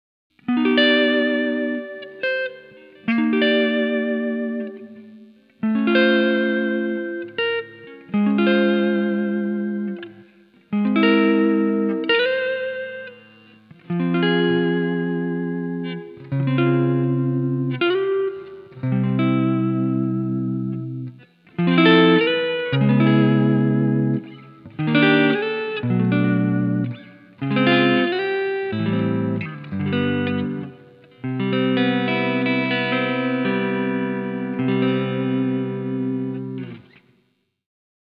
CUBE LITE - JC Clean example 850,03 ��
cube_lite_-_jc_clean.mp3